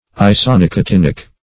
Search Result for " isonicotinic" : The Collaborative International Dictionary of English v.0.48: Isonicotinic \I`so*nic`o*tin"ic\, a. (Chem.)
isonicotinic.mp3